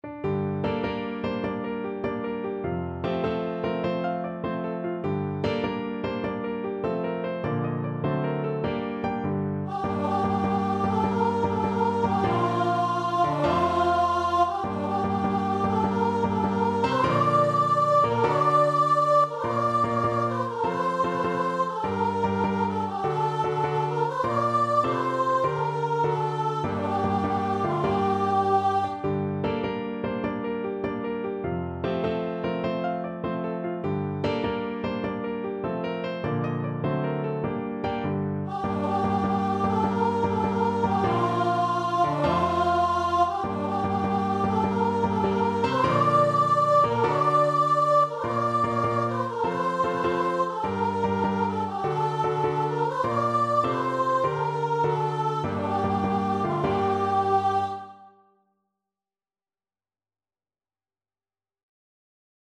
6/8 (View more 6/8 Music)
.=100 With spirit